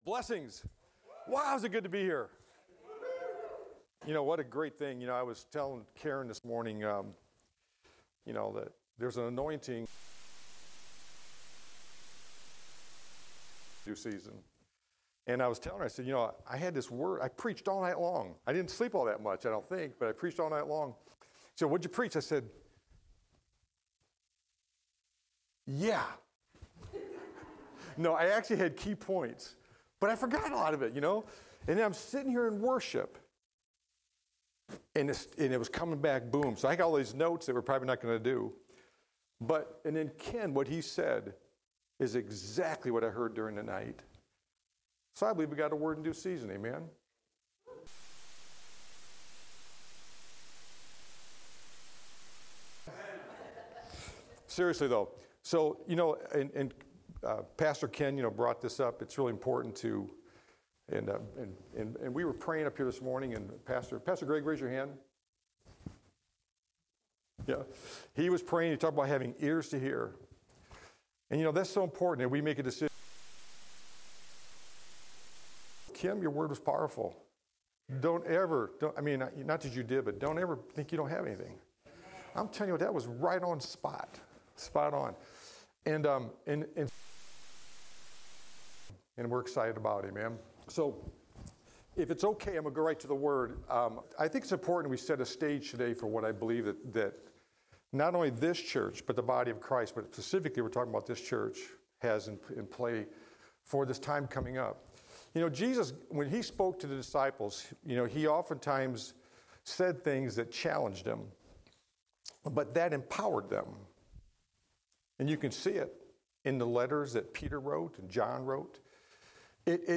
Service Type: Sunday Service In this sermon